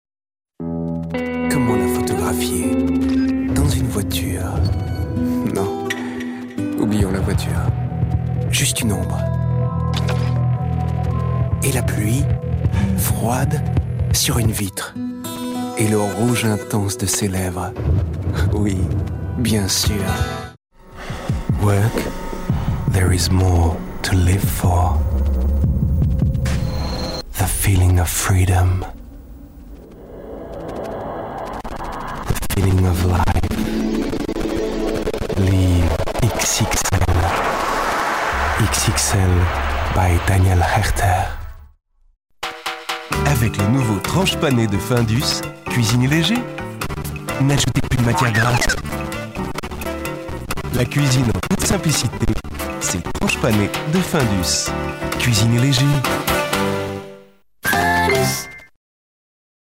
Kein Dialekt
Sprechprobe: Industrie (Muttersprache):
french voice over talent